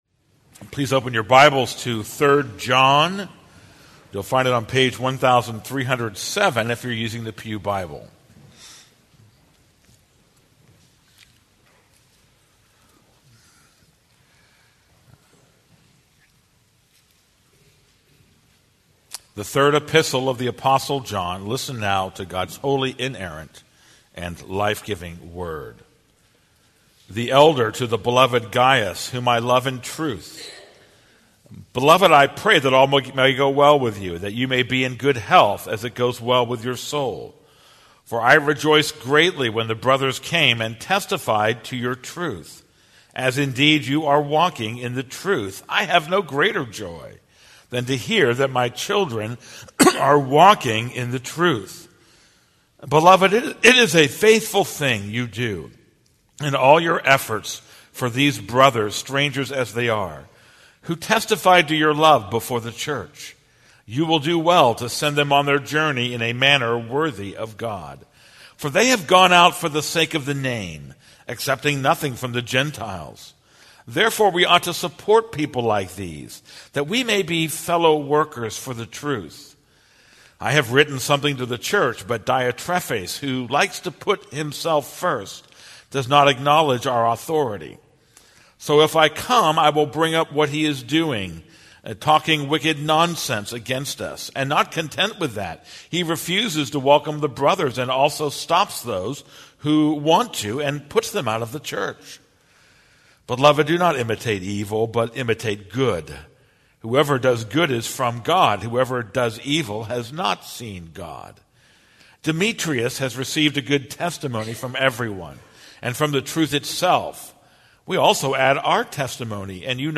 This is a sermon on 3 John 1:1-14.